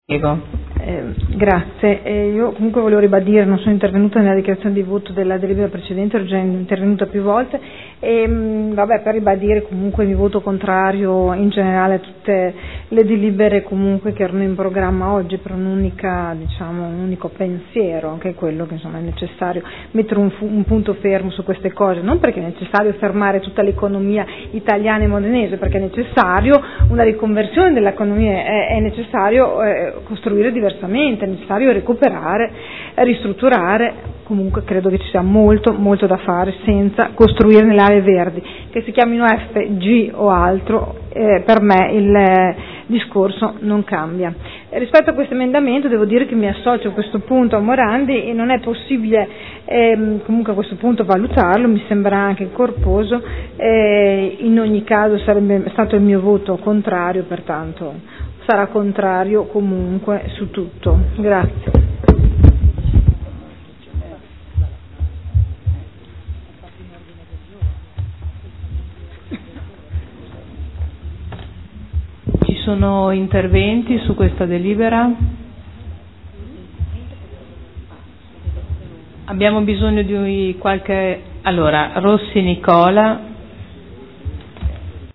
Sandra Poppi — Sito Audio Consiglio Comunale